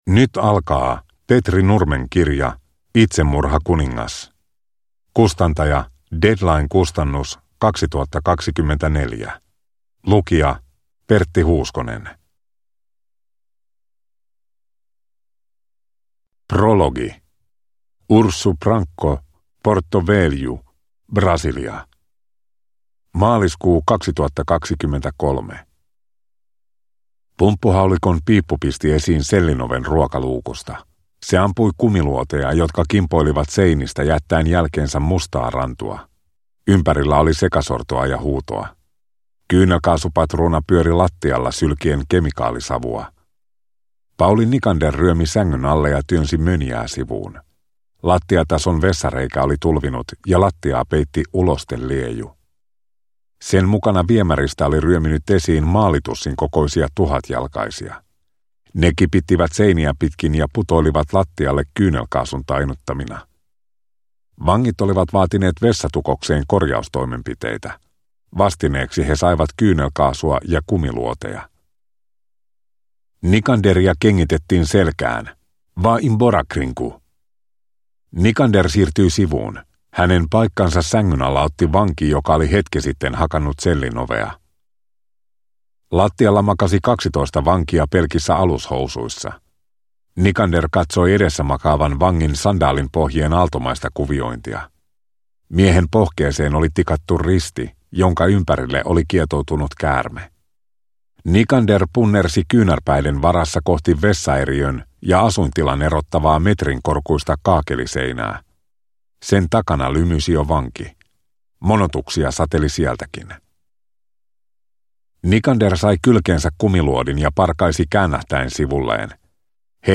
Itsemurhakuningas – Ljudbok